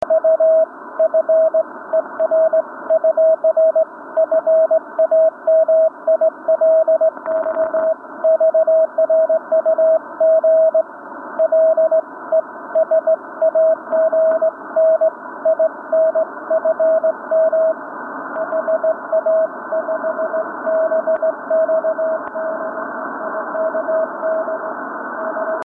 CW: Continuous Wave - Telegrafia
CW su 40 metri.mp3